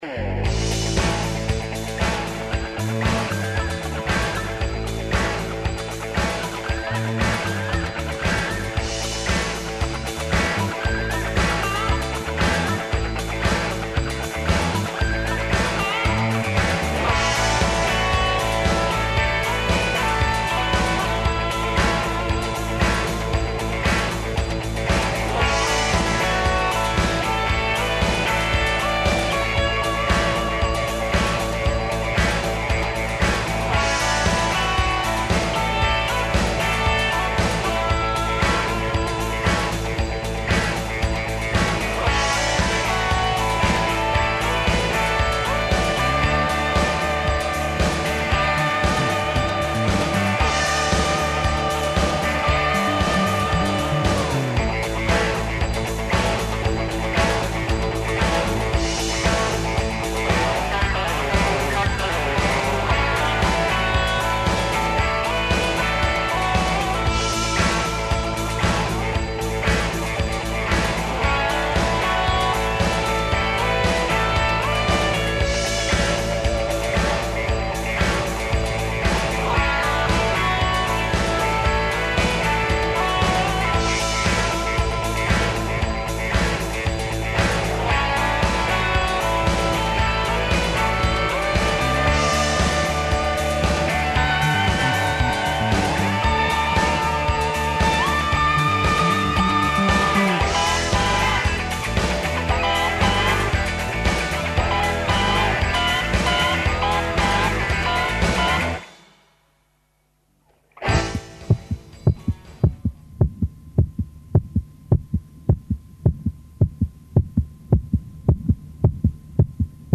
Акценат ће ипак бити на разговорима са многобројним значајним гостима из Европе и из наше земље у мобилном студију тик уз бину на којој ће се одвијати Међународна конференција националних паркова Европе.
У Пулсу ће и ноте бити "дунавске", тако да ће уживање и ове среде бити потпуно.